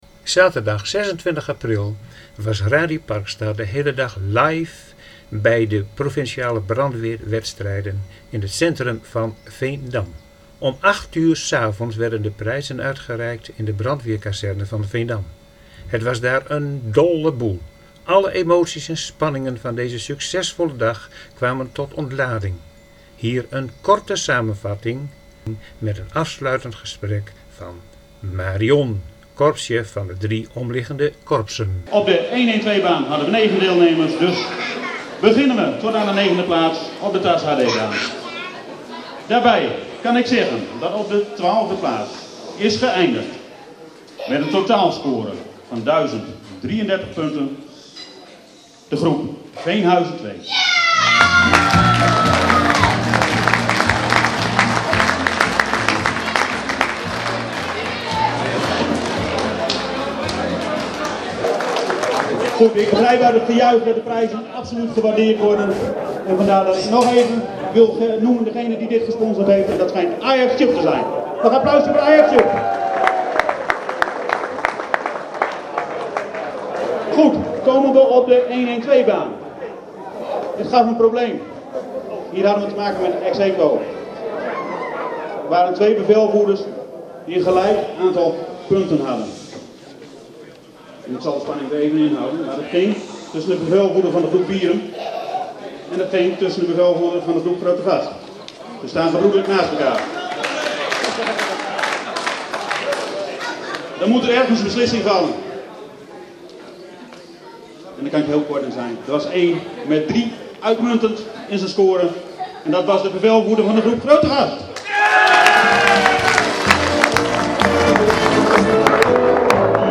Na een spannende dag was om 19.00 uur de prijsuitreiking in de brandweerkazerne aan de Langeleegte. Nadat de jury had uitgelegd hoe zij hadden gedacht dat de scenario's moesten worden aangepakt, volgde de uitslag.